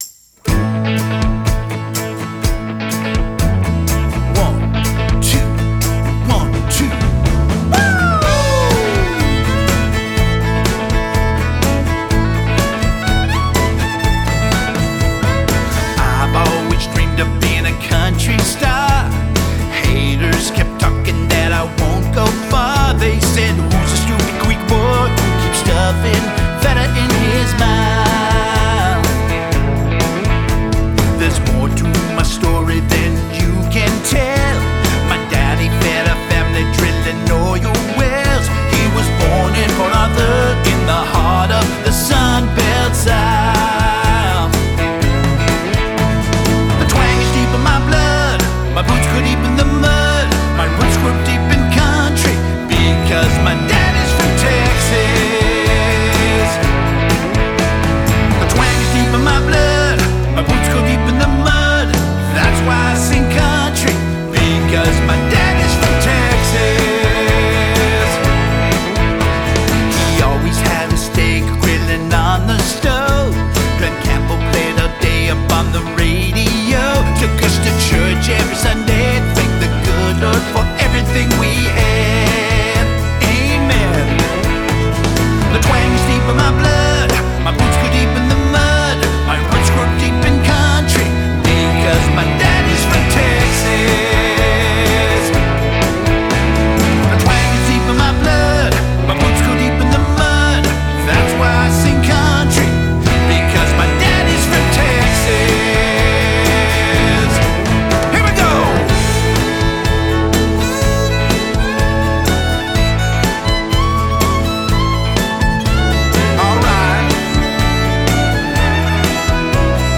Country pop song